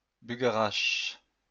Bugarach (prononcé : /by.ga.ʁaʃ/